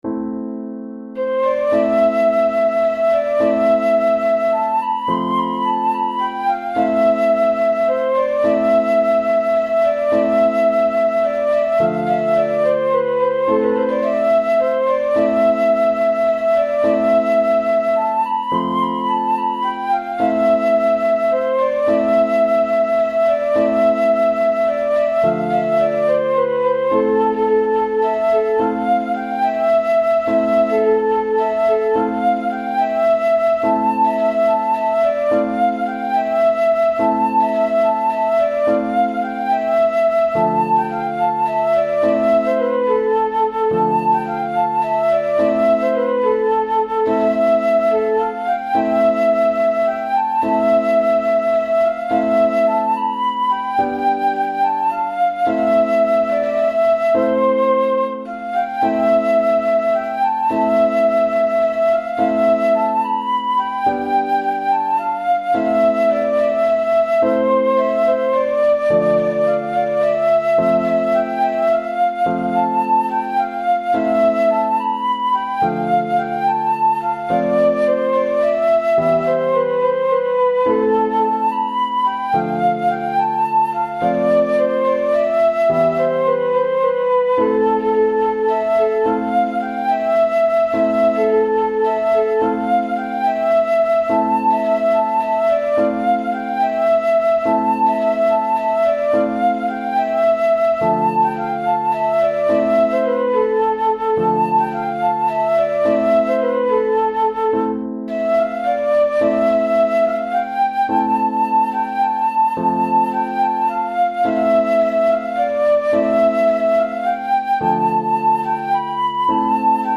ساز : فلوت